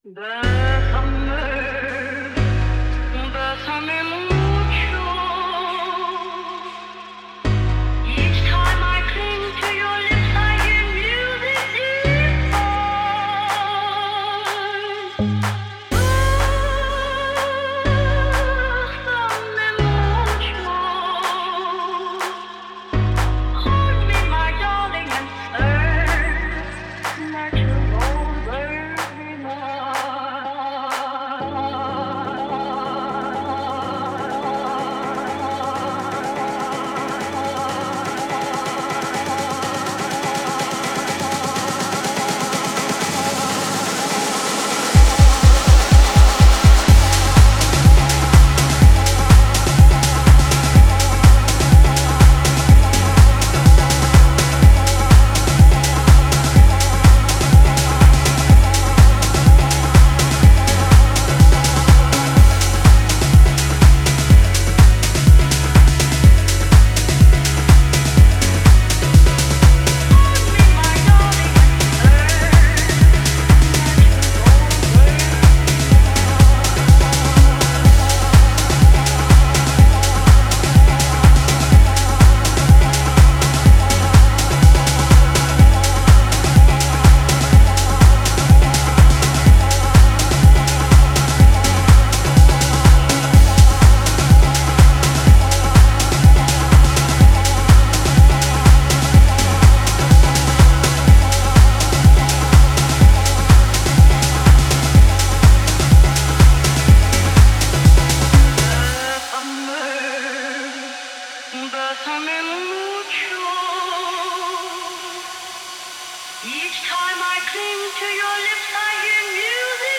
Promo Text:House, Piano House
12A - 124bpm House - Piano House